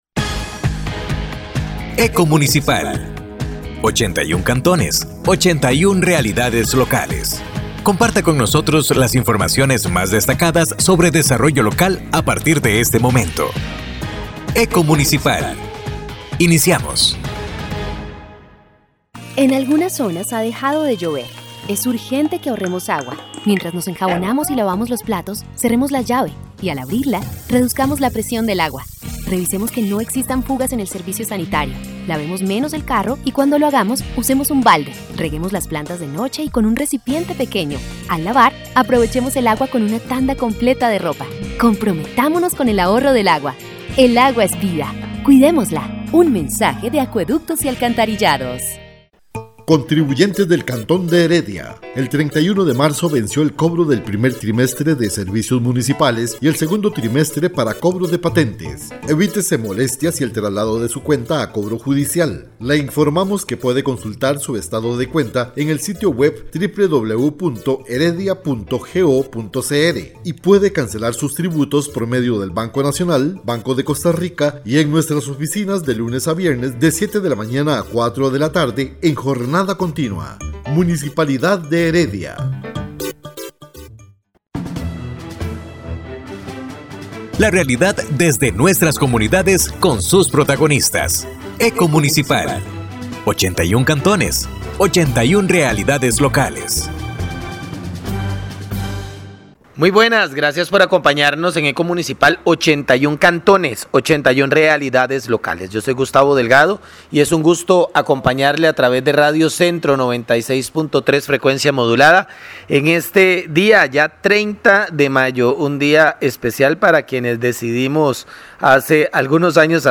Programa de Radio Eco Municipal